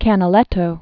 (kănə-lĕtō) Originally Giovanni Antonio Canal. 1697-1768.